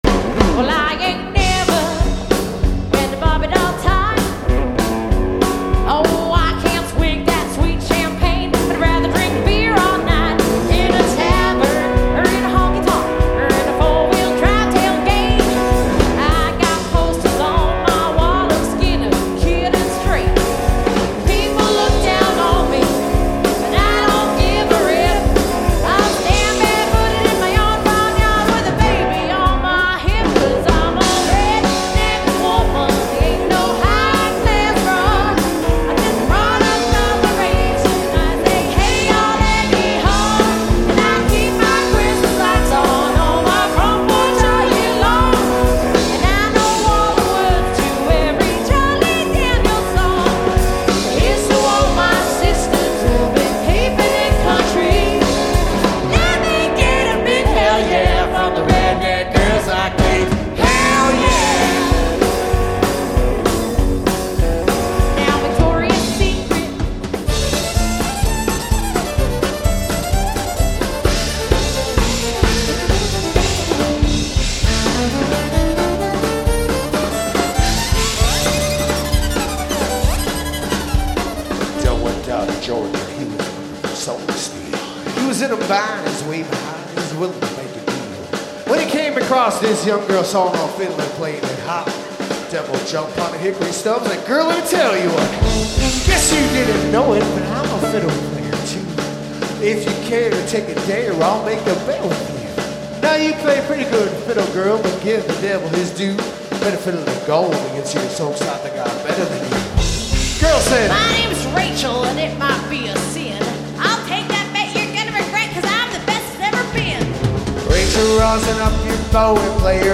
Lead Vocals, Guitar
Mandolin
Violin
Drums
Bass